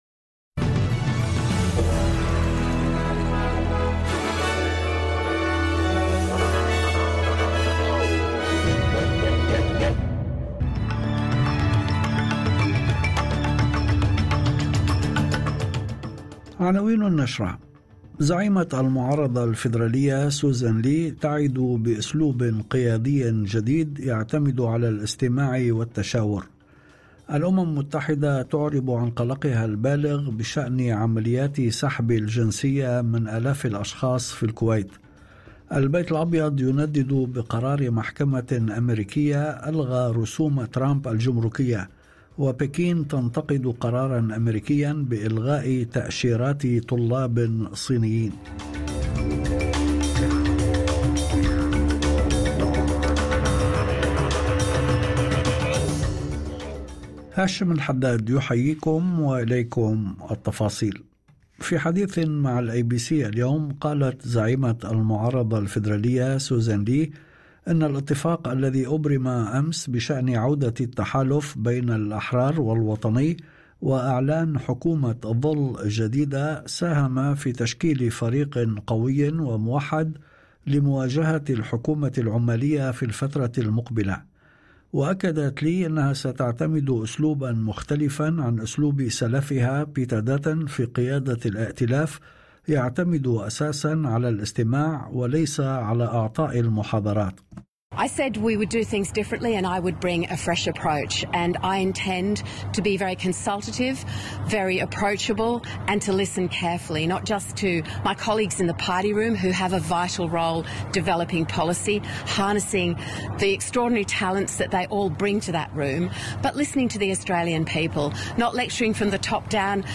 نشرة أخبار المساء 29/05/2025